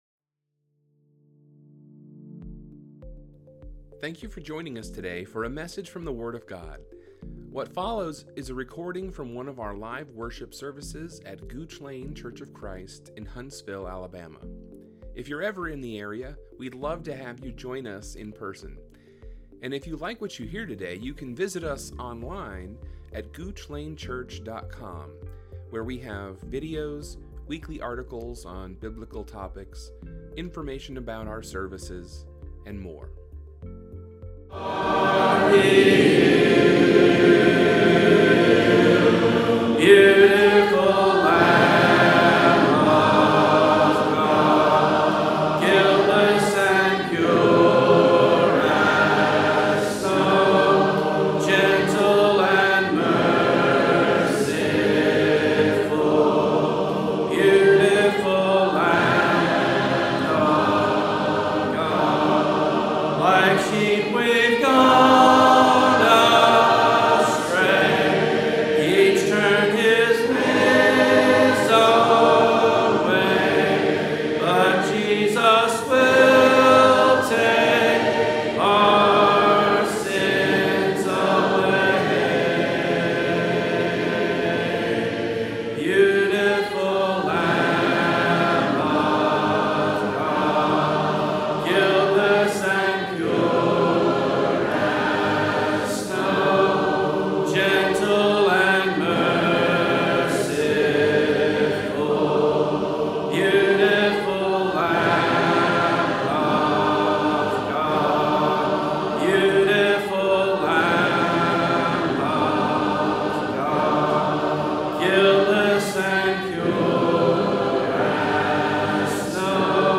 This "Questions & Answers" sermon focused on three questions submitted by attendees in the past few months.